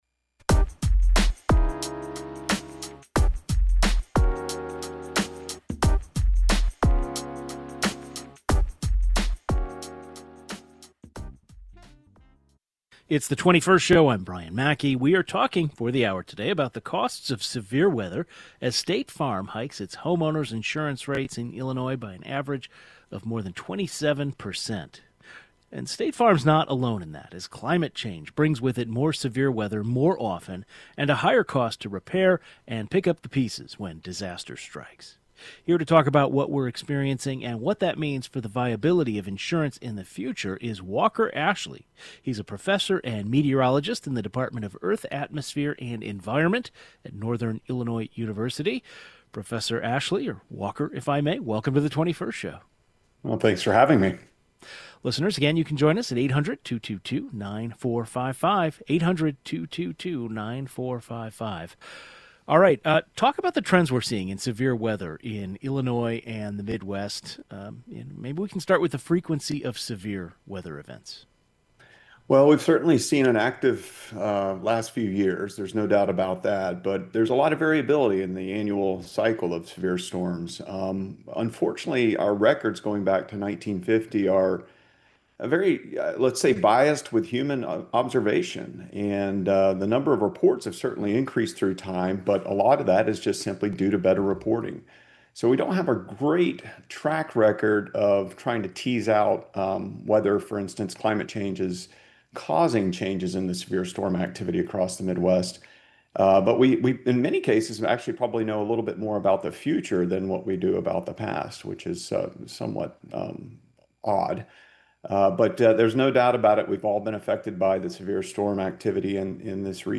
A meteorologist told us what we're experiencing and what that means for the viability of insurance in the future.